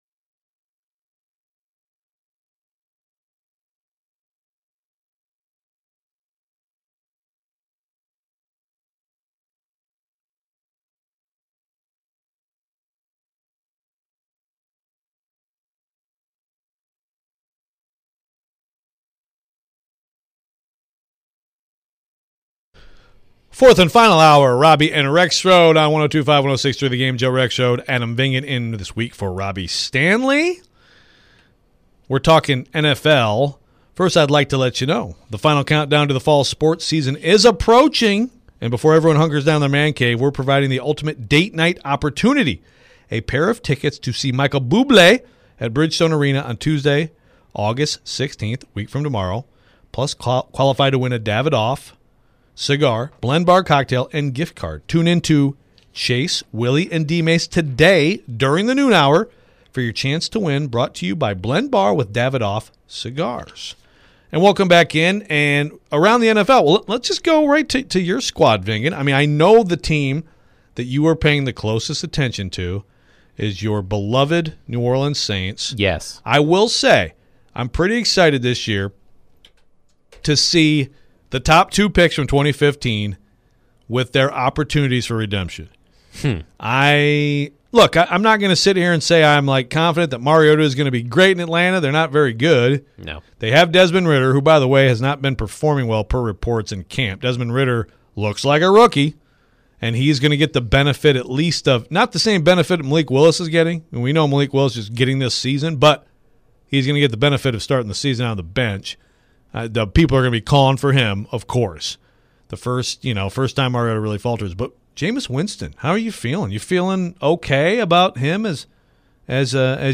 live from the Titans Training Camp